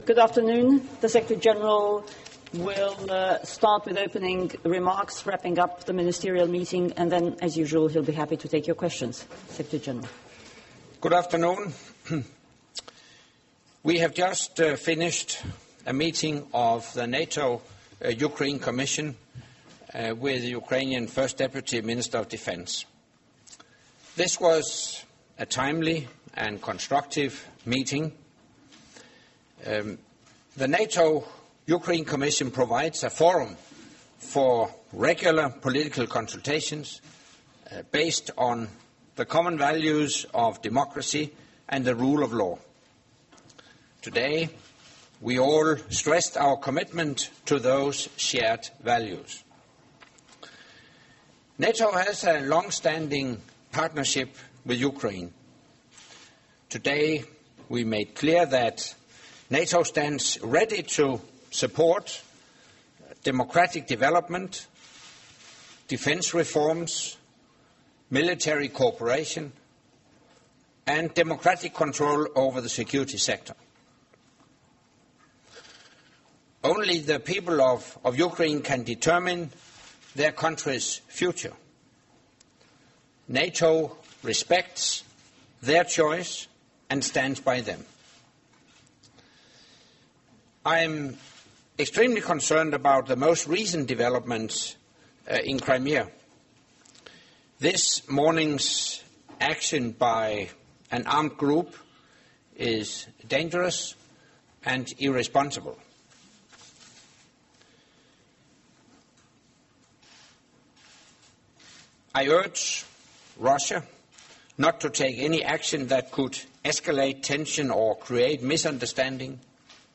Press conference by NATO Secretary General Anders Fogh Rasmussen following the meetings of the NATO Defence Ministers